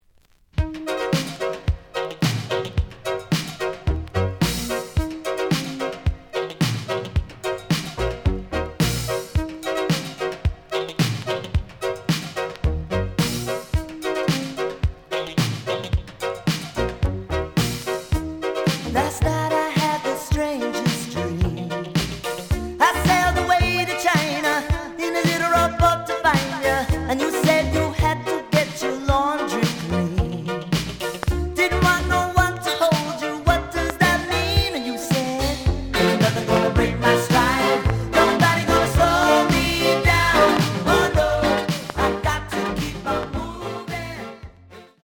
The audio sample is recorded from the actual item.
●Format: 7 inch
●Genre: Rock / Pop